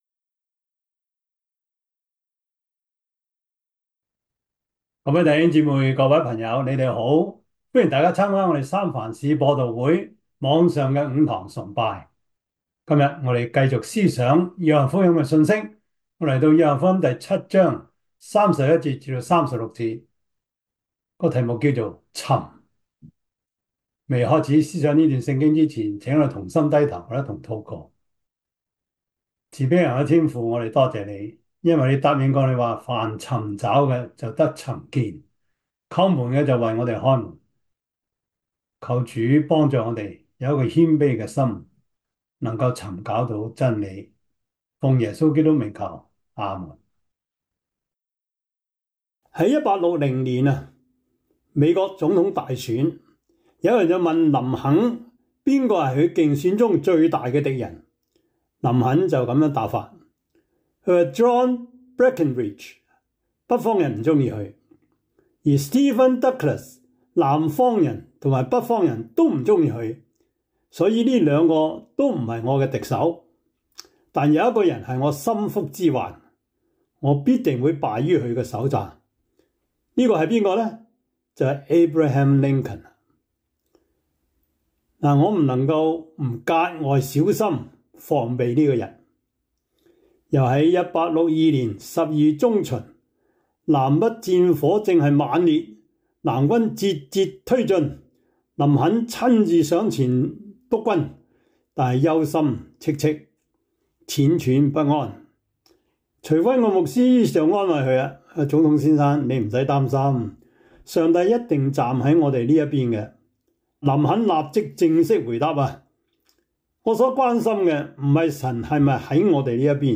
約翰福音 7:31-36 Service Type: 主日崇拜 約翰福音 7:31-36 Chinese Union Version